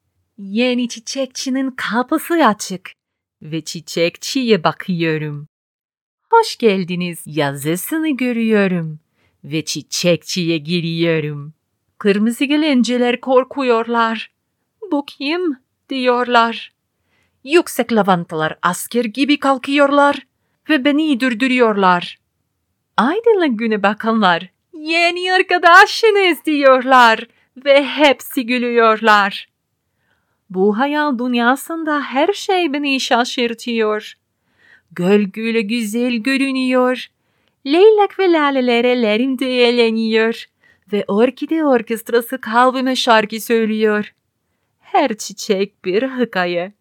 Female
Turkish: Playful, Animated